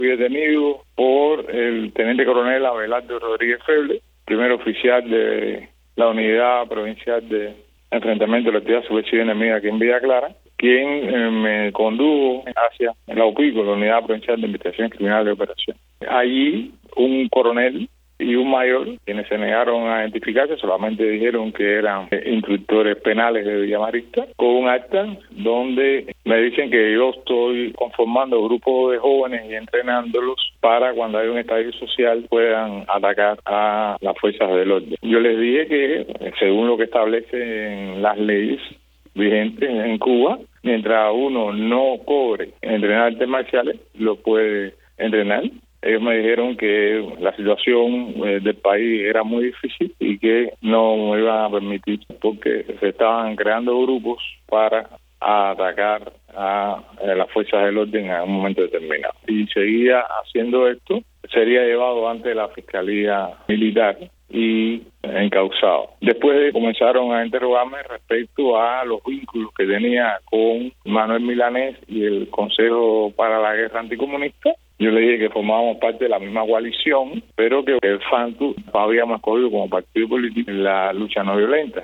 Tras ser liberado, el activista habló con Martí Noticias sobre el intento de la policía política de involucrarle con la "Lista Nacional de Terrorismo" hecha pública la semana pasada por el régimen cubano, y que incluye a personas y organizaciones supuestamente vinculadas a actos terroristas contra la isla.